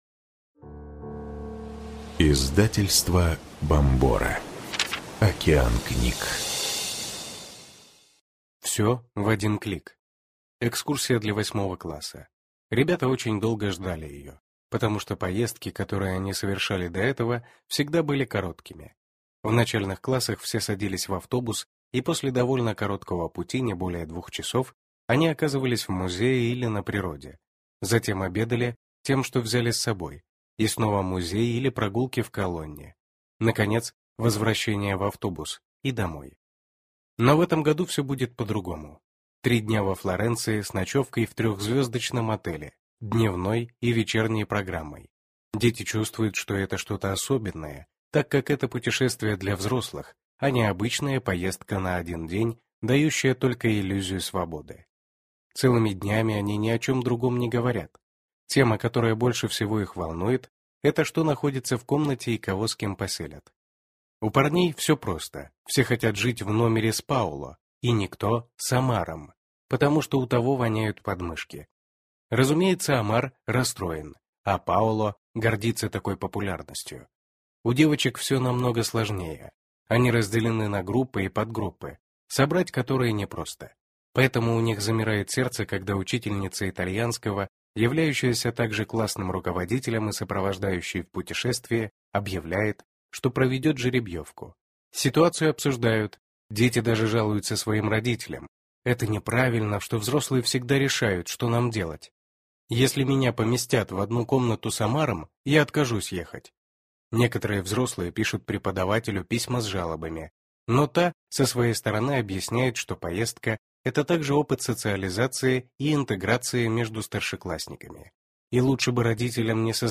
Аудиокнига Слишком рано. Сексвоспитание подростков в эпоху интернета | Библиотека аудиокниг